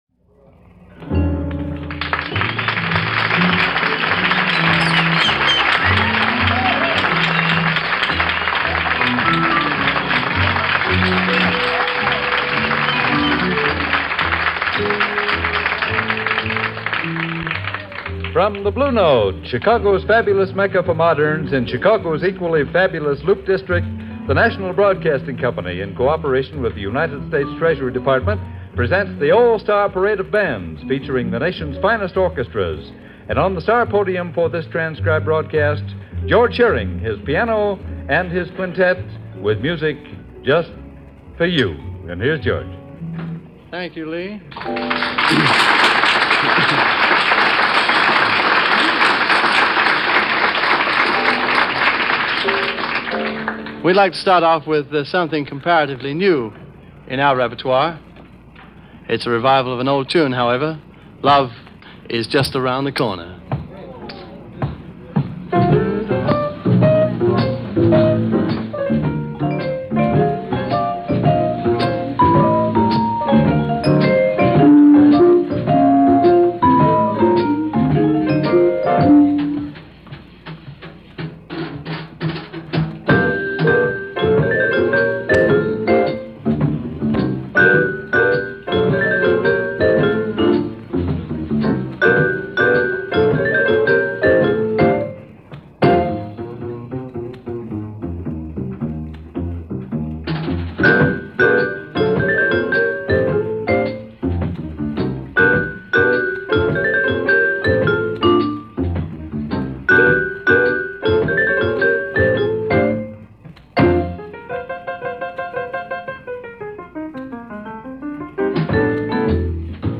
Heading into some Jazz for Fall Weather this weekend.
vibes
high priest of mellow-cool. https